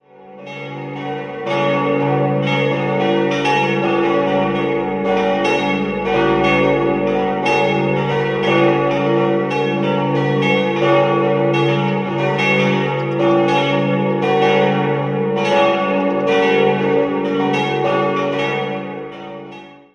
Westminster-Geläut: cis'-fis'-gis'-ais' Die Glocken 4 und 2 wurden 1889 bzw. 1886 von Fritz Hamm in Augsburg gegossen, die anderen beiden goss 1958 Rudolf Perner in Passau.